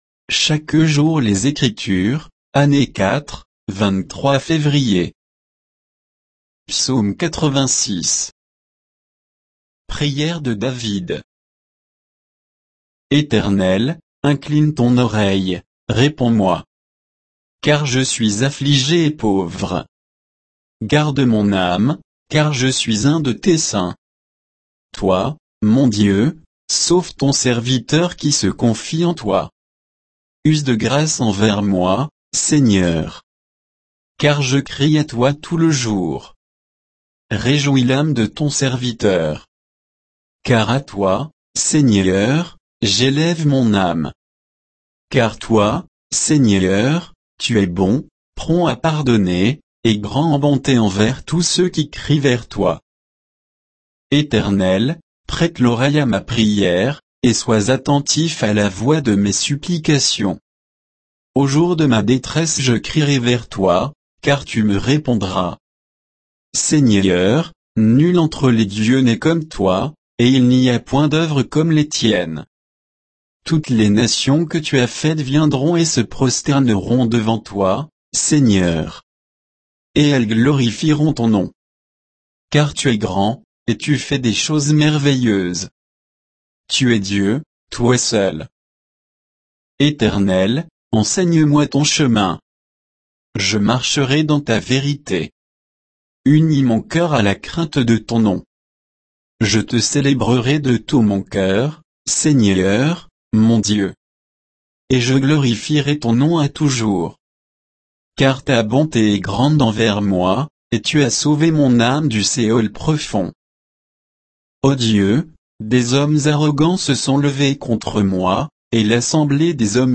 Méditation quoditienne de Chaque jour les Écritures sur Psaume 86